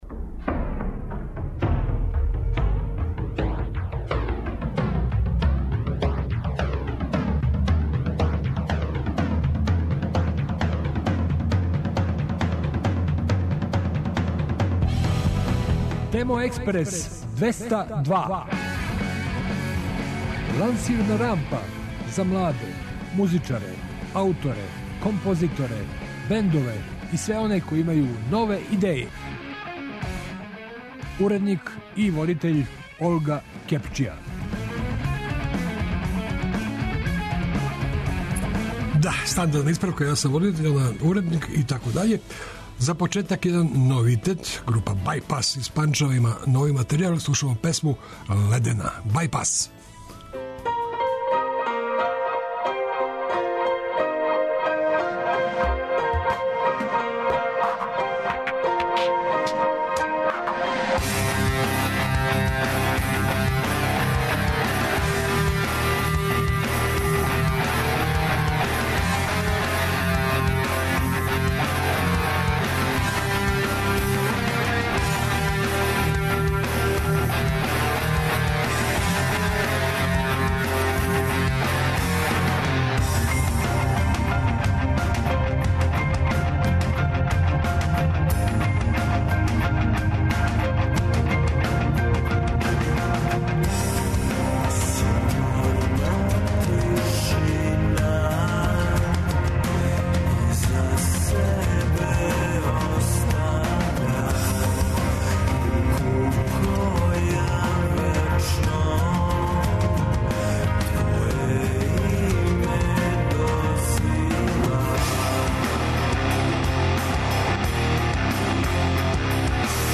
И у овонедељној емисији вас очекује много нове музике, интересантних нових бендова и издања.